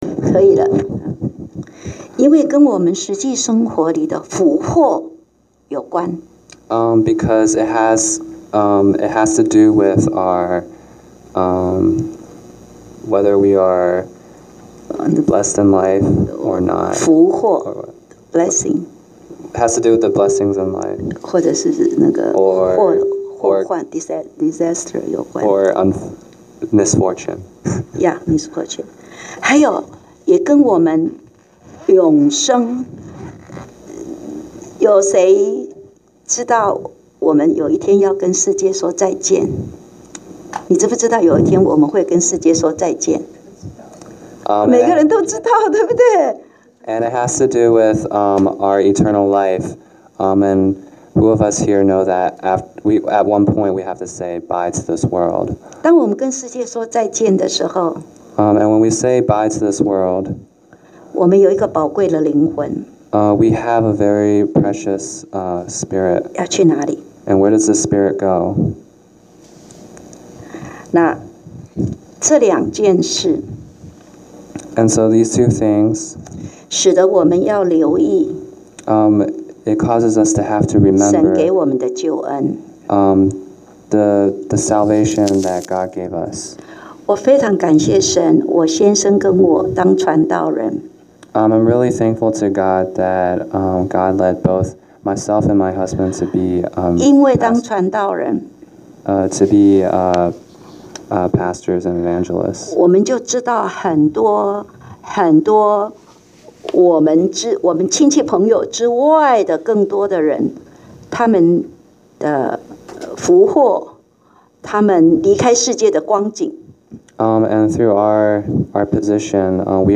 講道下載